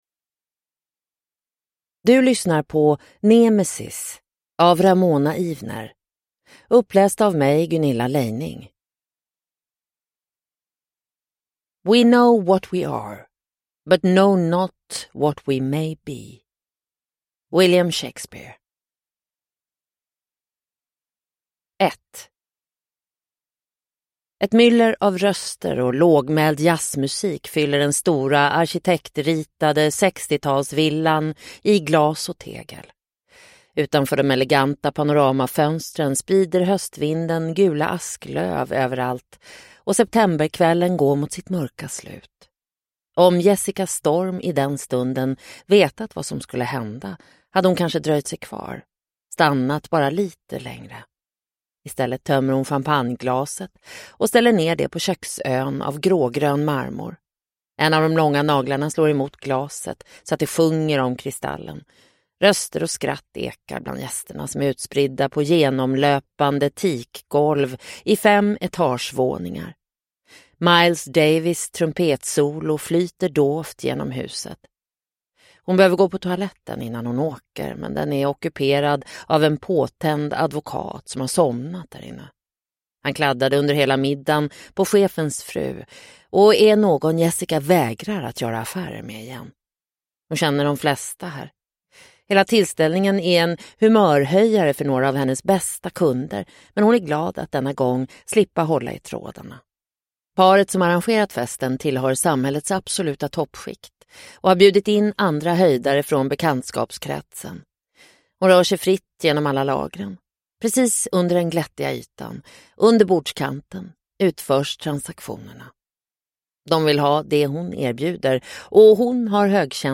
Nemesis (ljudbok) av Ramona Ivener